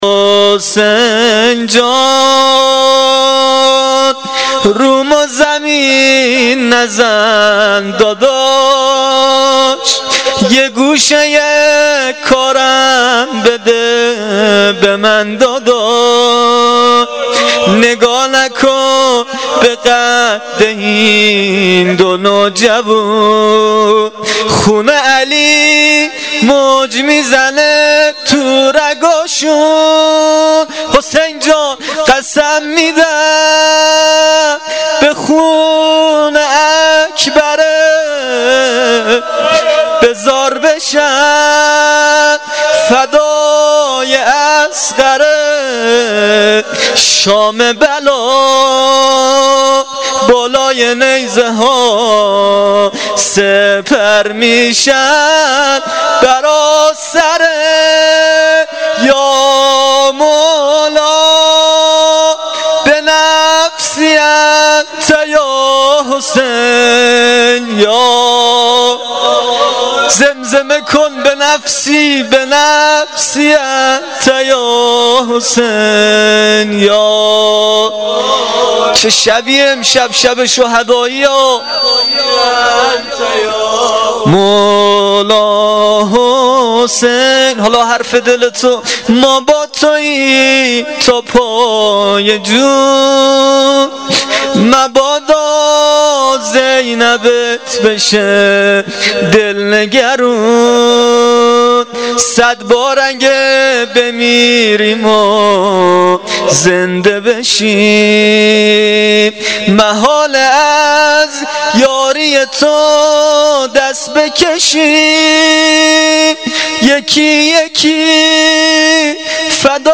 روضه شب چهارم محرم الحرام 1396
• هیئت جواد الائمه